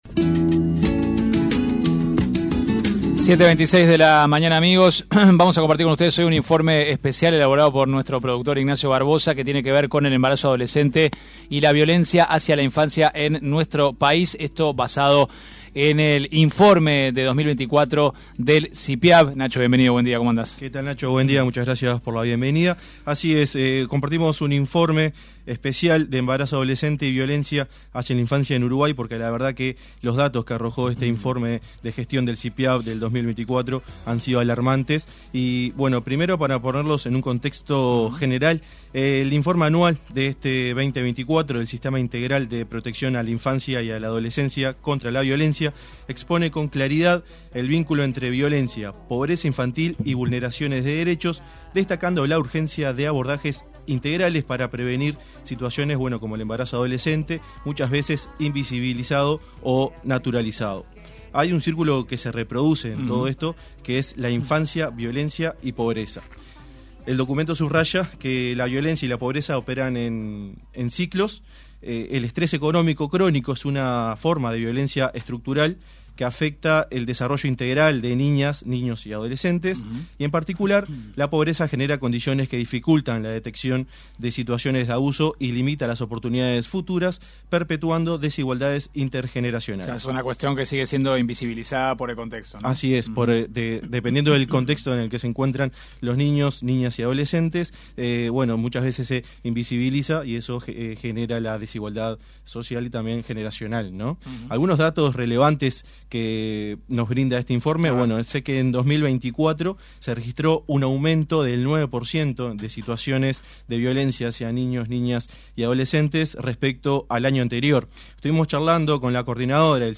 Informe especial: Embarazo adolescente y violencia hacia la infancia en Uruguay - 970 Universal